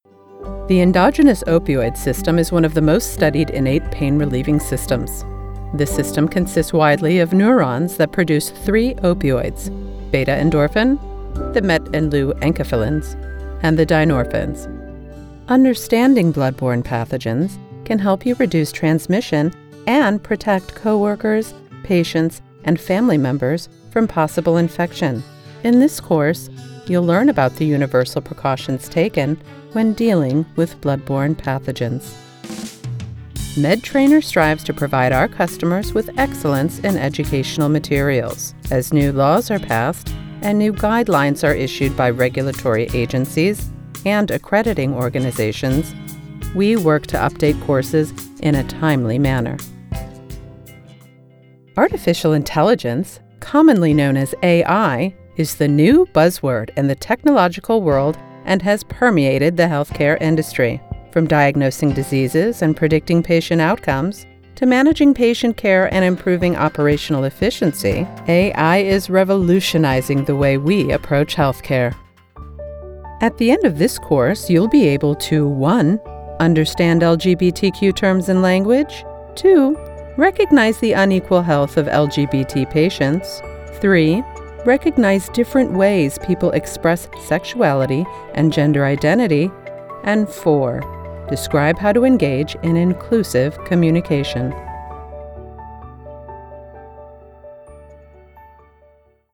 Broadcast Quality Voiceover Talent and Certified Audio Engineer
e-Learning
Medical
Working from my broadcast-quality home studio is not just my profession—it’s my joy.